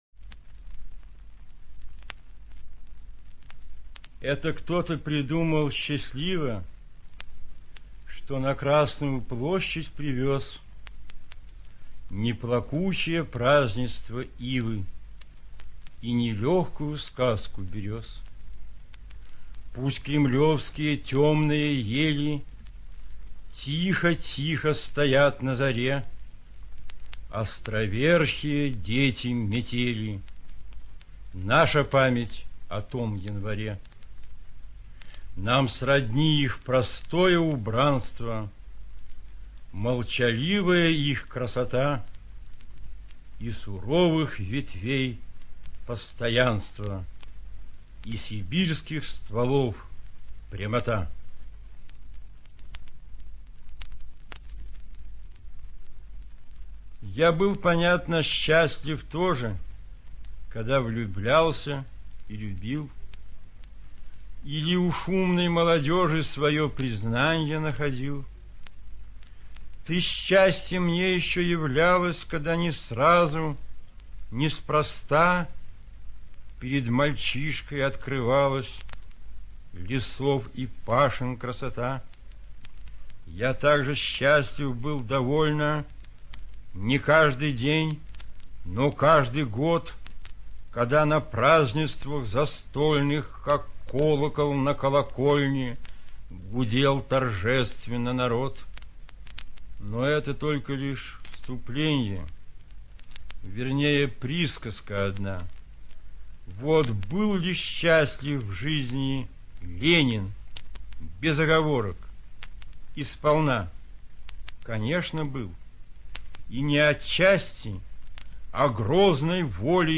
ЧитаетАвтор
ЖанрПоэзия